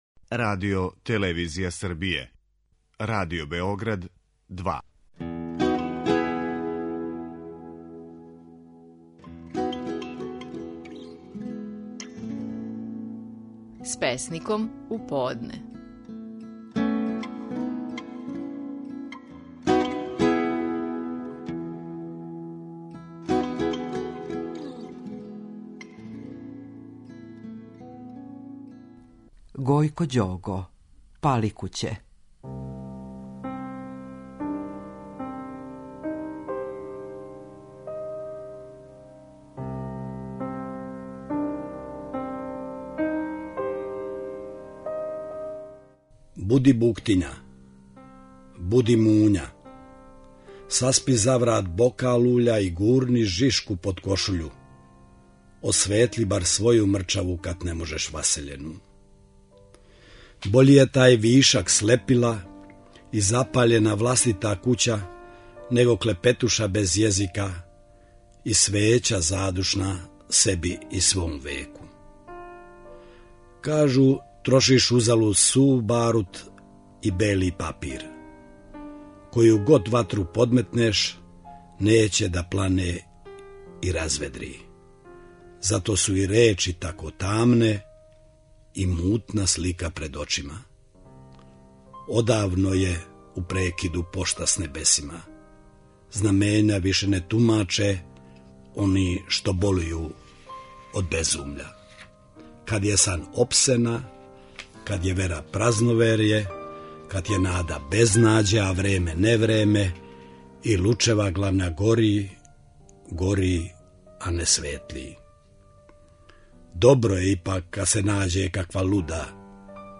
Стихови наших најпознатијих песника, у интерпретацији аутора.
Гојко Ђого говори песму „Паликуће".